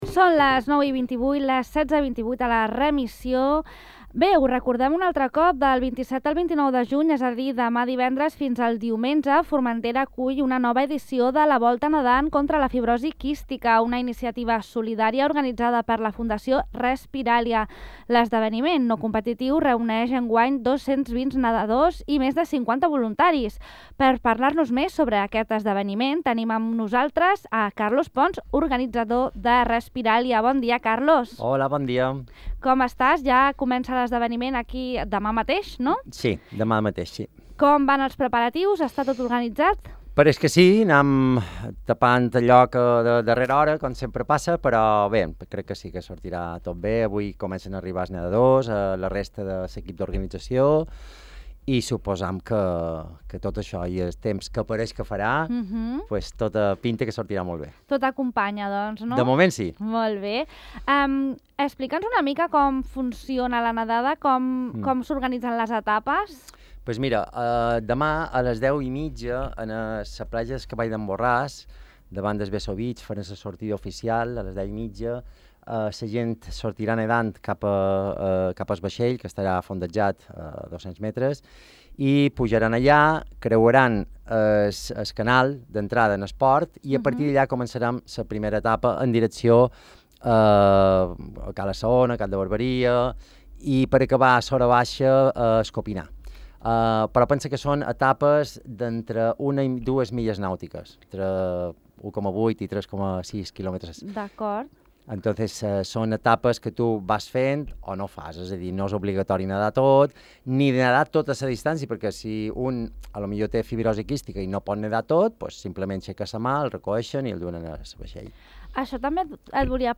entrevistat